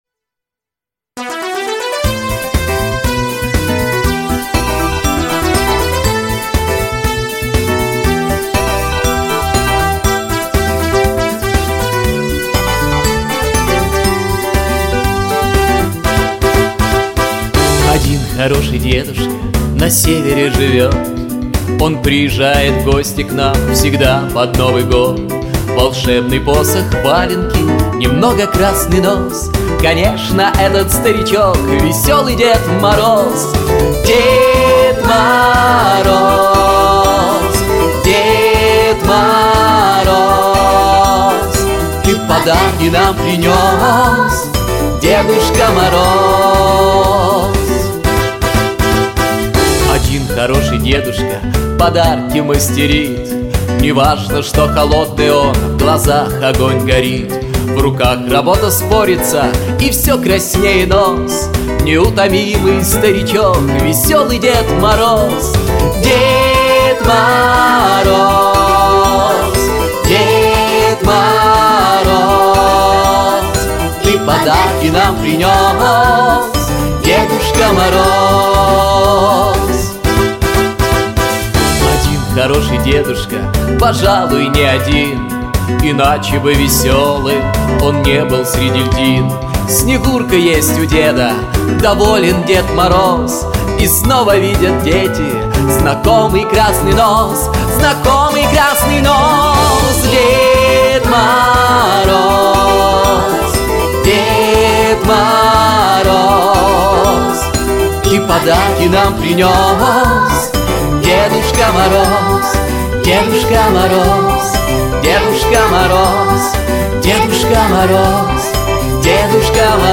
🎶 Детские песни / Песни на Новый год 🎄